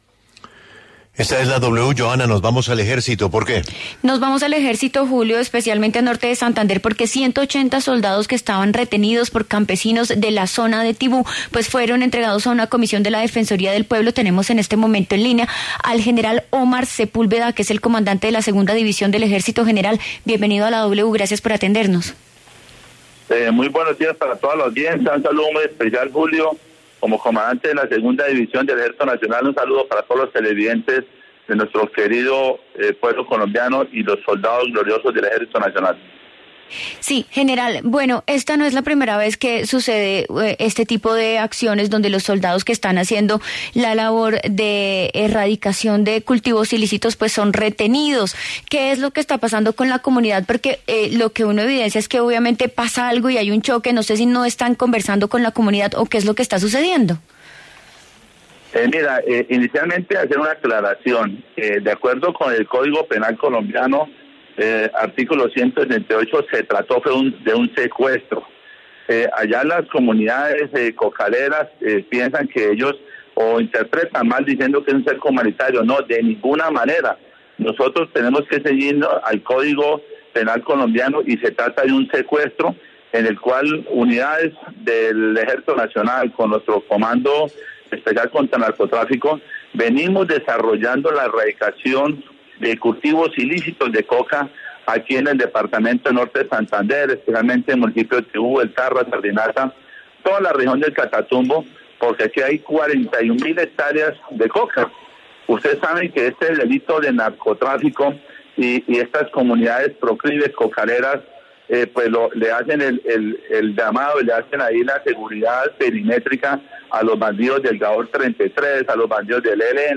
En diálogo con La W, el mayor general Omar Sepúlveda se pronunció sobre las acciones que se adoptarán tras el secuestro de 140 soldados en el Catatumbo.